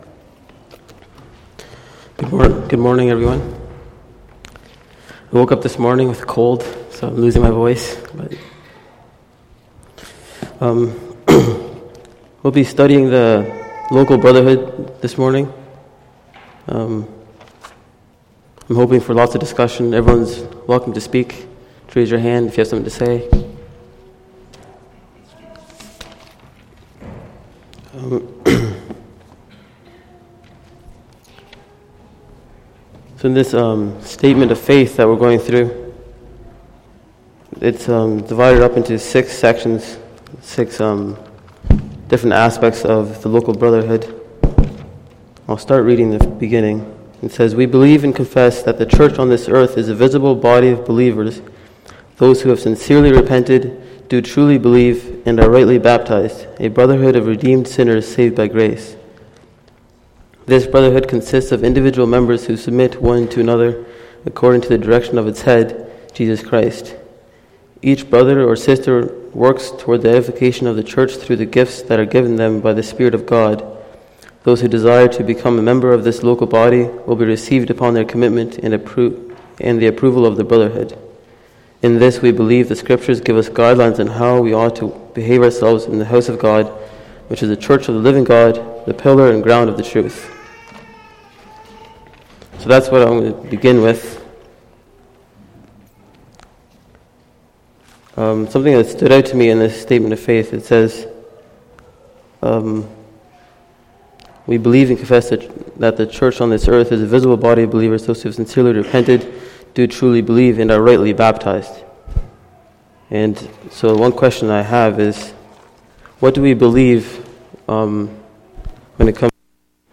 Sunday Morning Bible Study Service Type